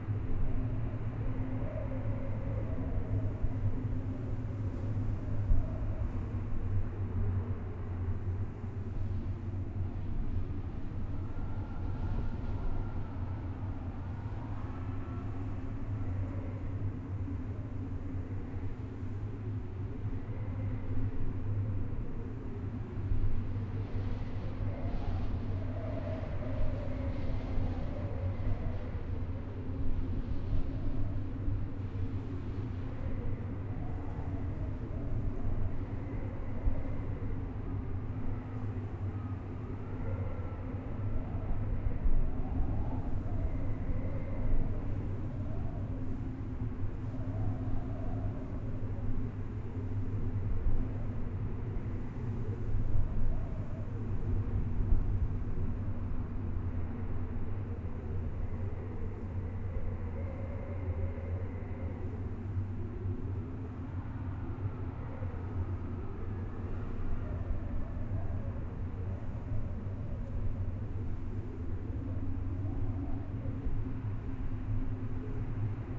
pgs/Assets/Audio/Sci-Fi Sounds/Hum and Ambience/Wind Loop 1.wav at 7452e70b8c5ad2f7daae623e1a952eb18c9caab4
Wind Loop 1.wav